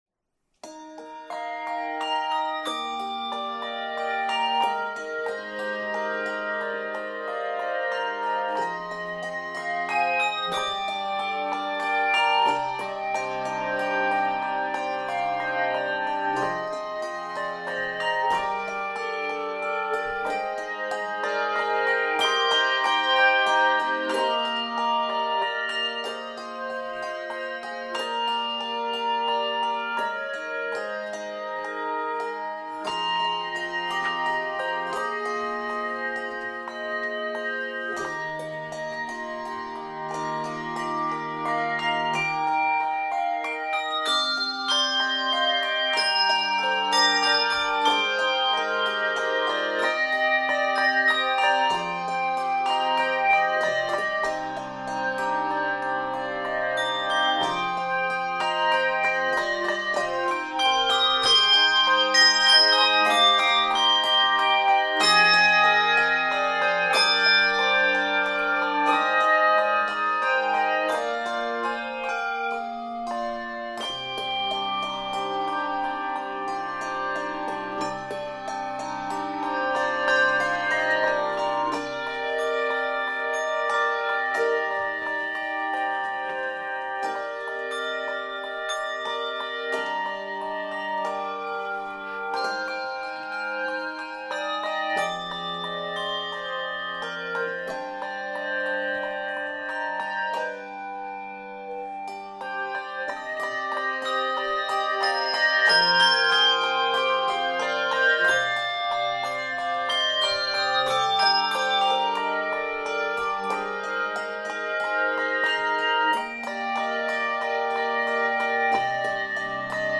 Written in a breezy, lyrical and flowing style
Key of Eb Major.